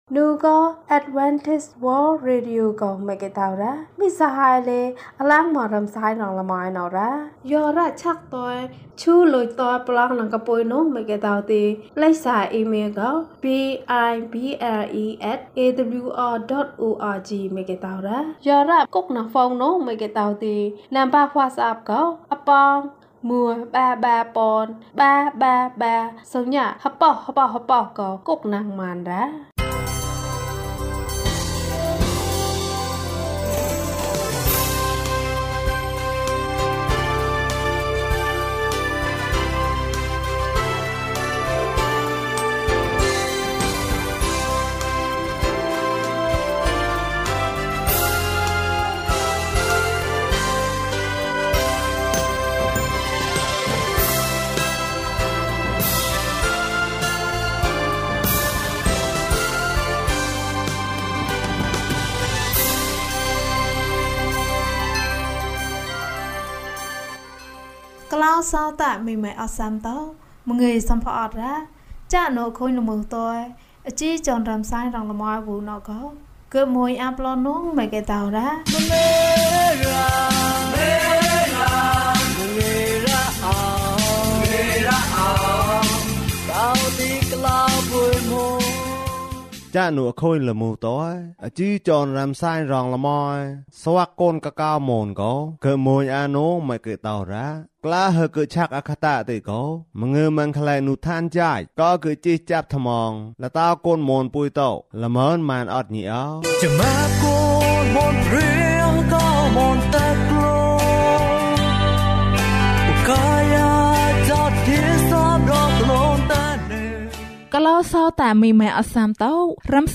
အချိန်တိုင်းနှင့်ယေရှု။ ကျန်းမာခြင်းအကြောင်းအရာ။ ဓမ္မသီချင်း။ တရားဒေသနာ။